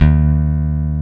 EPM E-BASS.1.wav